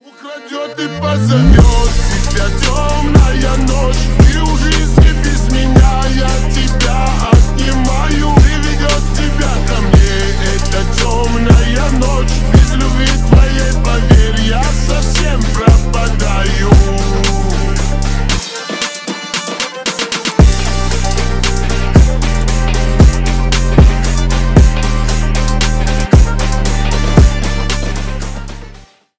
Ремикс # Поп Музыка # кавказские